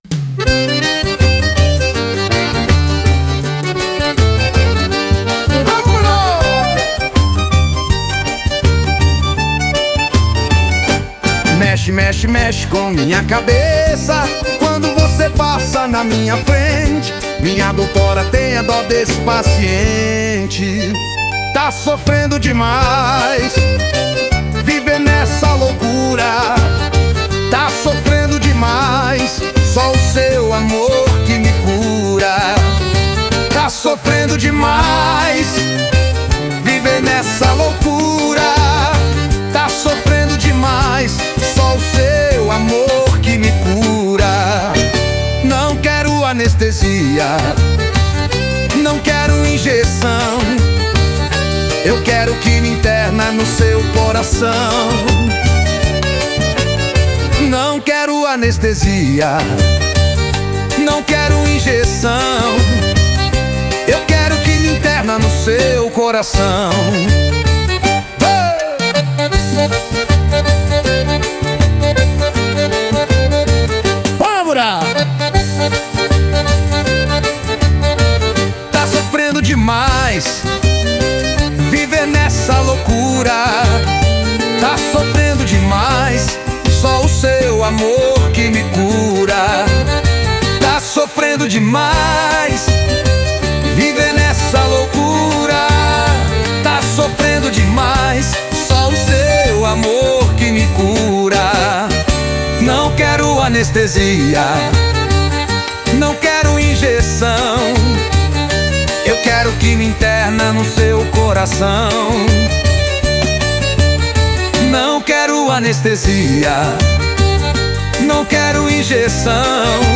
Forró com alma nordestina